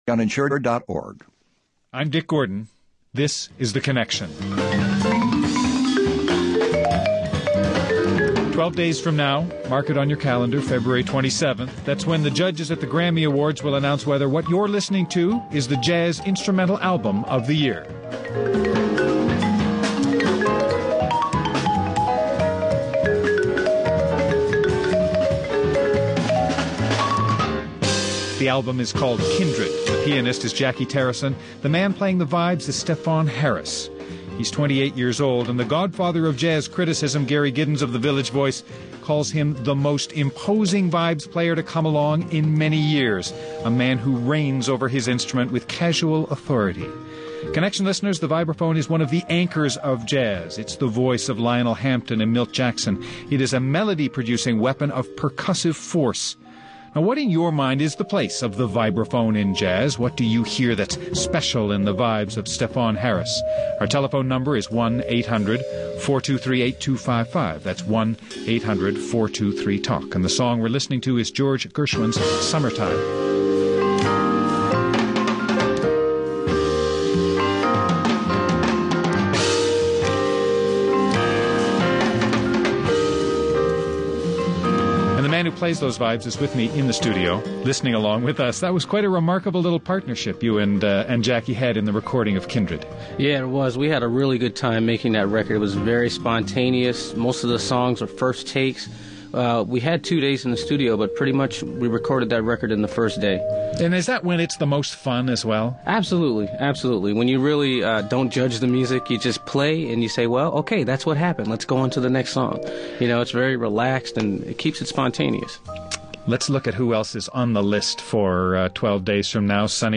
And he speaks, on his instrument, in sometimes blistering, sometimes cool, always sublime tones.
Guests: Stefon Harris, jazz musician